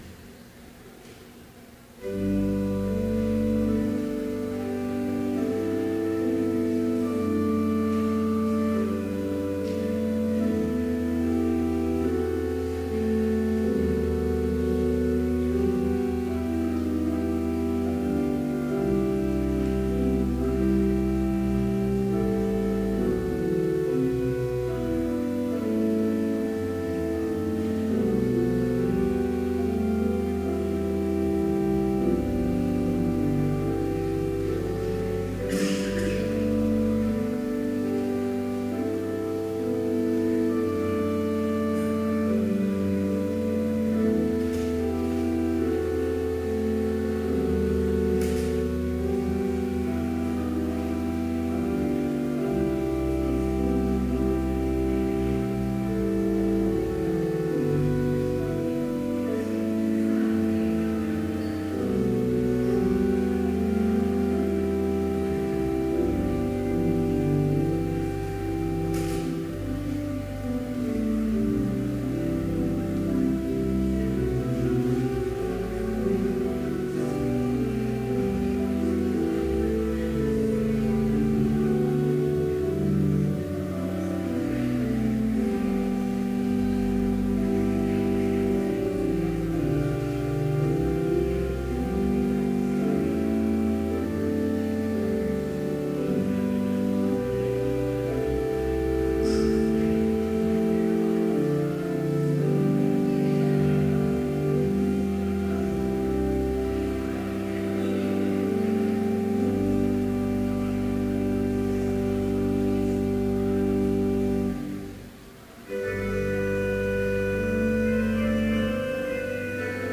Complete service audio for Chapel - October 13, 2014